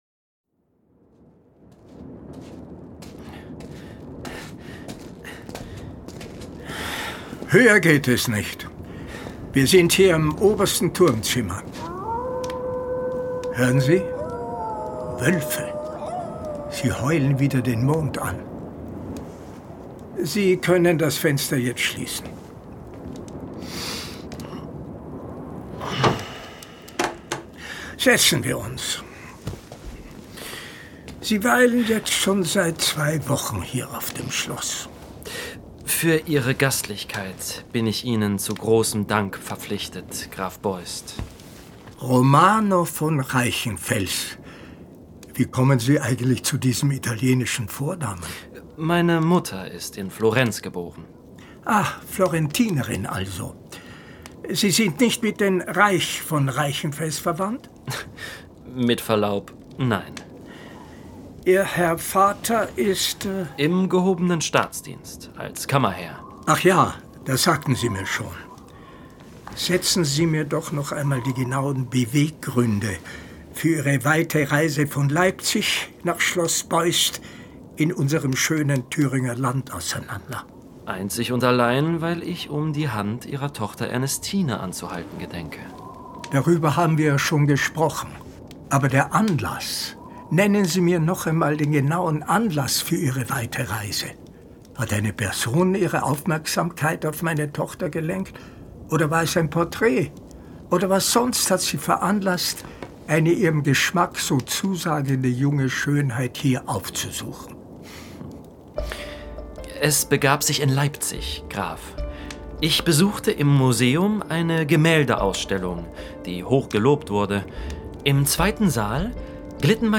Die Folge erzeugt eine düstere, spannungsgeladene Atmosphäre, die durch markante Szenen und ein geschickt eingewobenes Verwirrspiel ergänzt wird.
Dennoch überzeugt die Folge vor allem durch ihre dichte, unheilvolle Stimmung, die im Verlauf des Hörens an Komplexität gewinnt, obwohl Schauplätze und Figuren begrenzt sind.
Das phantastische Hörspiel